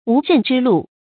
無任之祿 注音： ㄨˊ ㄖㄣˋ ㄓㄧ ㄌㄨˋ 讀音讀法： 意思解釋： 謂不做事或不會做事但得到祿位。